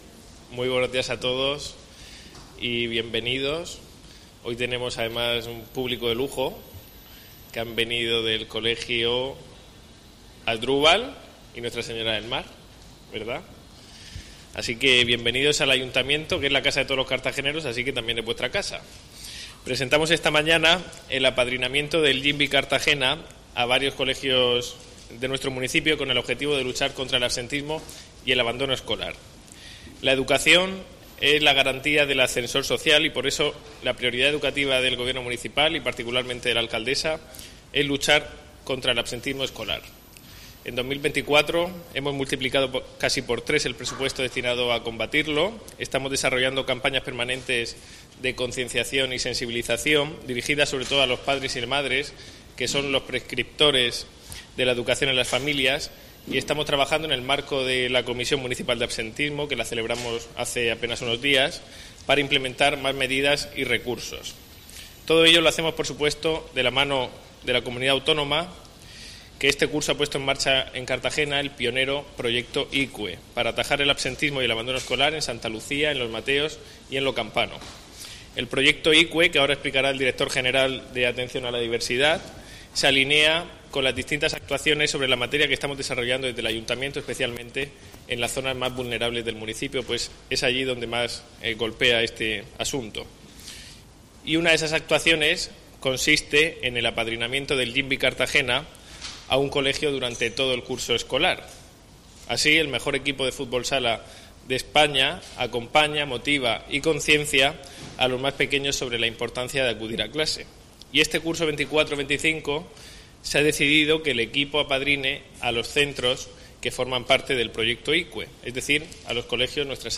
Enlace a Presentación del apadrinamiento del Jimbee Proyecto Icue contra el absentismo escolar